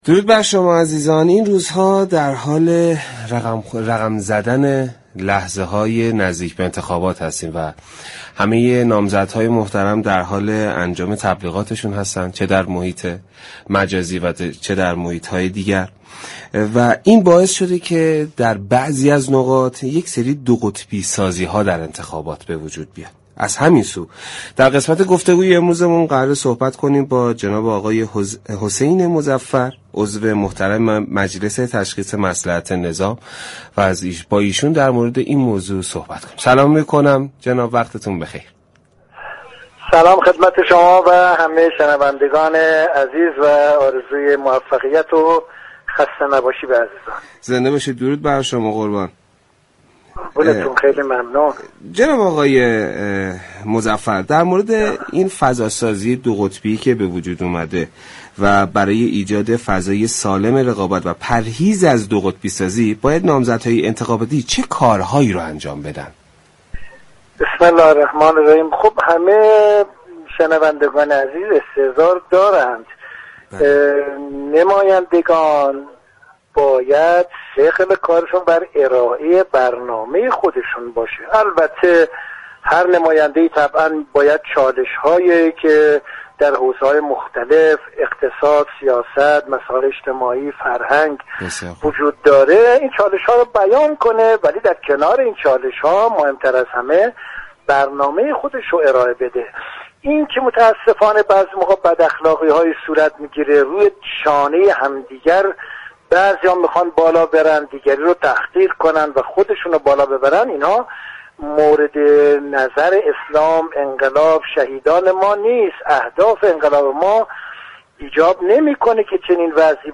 به گزارش پایگاه اطلاع رسانی رادیو تهران، حسین مظفر در گفتگو با برنامه سعادت آباد رادیو تهران درباره ایجاد فضای سالم رقابتی و پرهیز از دوقطبی سازی فضای انتخاباتی گفت: ثقل كارنمایندگان باید بر ارائه برنامه خودشان در حوزه های مختلف باشد و در كنار برنامه ها، چالش ها را بیان كنند.